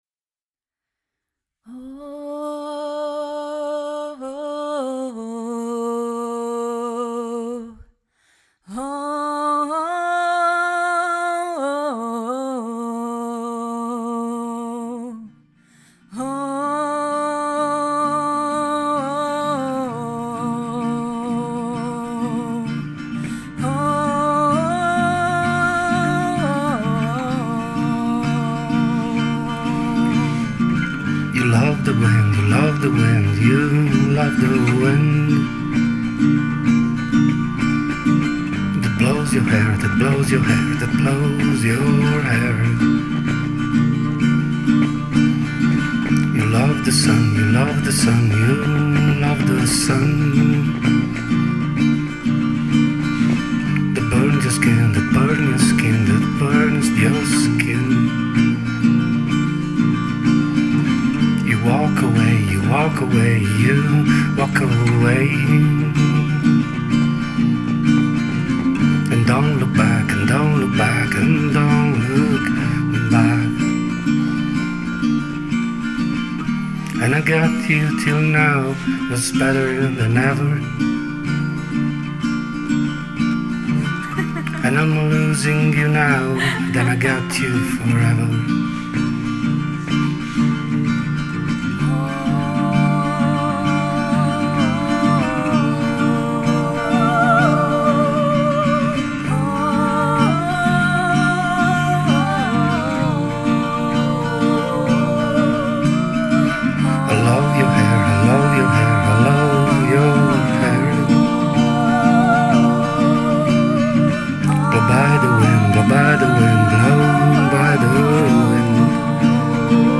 folk emozionante